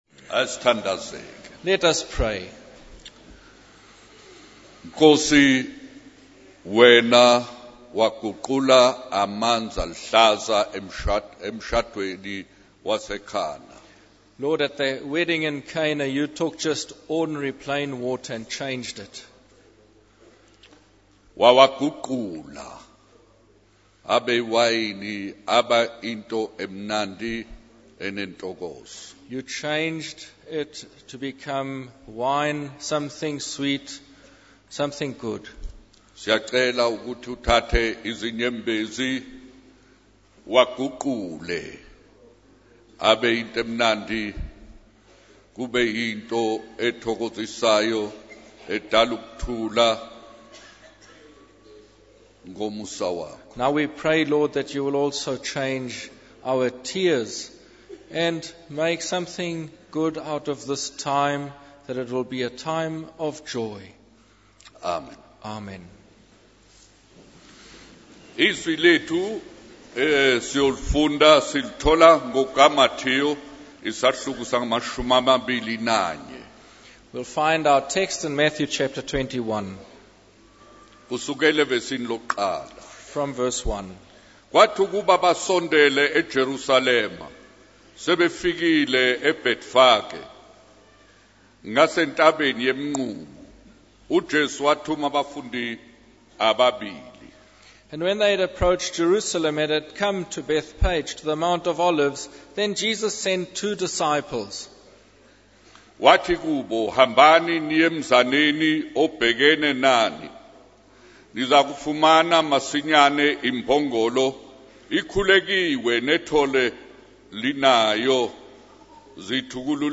In this sermon, the preacher reflects on the life and testimony of a woman who lived a good life and passed away. He emphasizes the importance of following in the footsteps of Christian parents and serving the Lord.